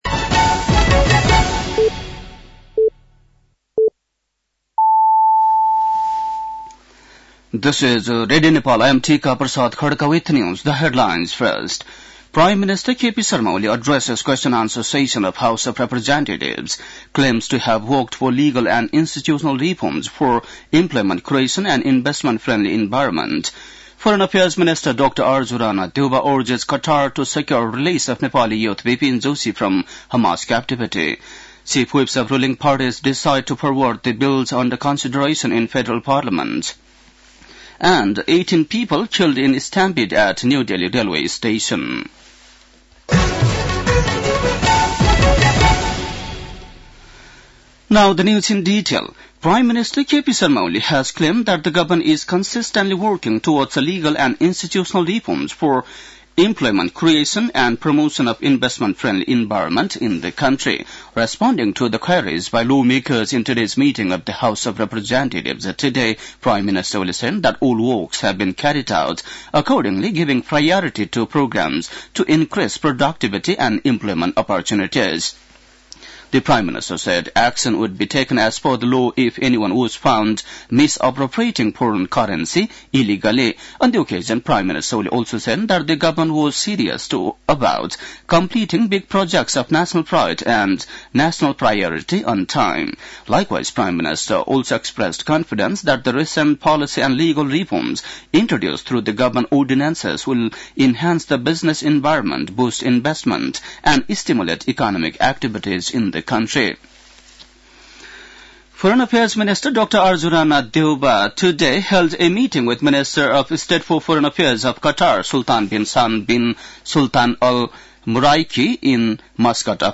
बेलुकी ८ बजेको अङ्ग्रेजी समाचार : ५ फागुन , २०८१
8-pm-english-news-11-04.mp3